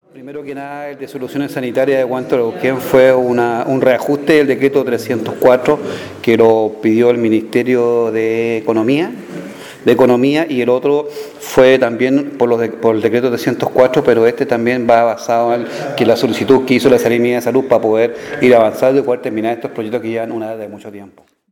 El consejero Juan Barraza dijo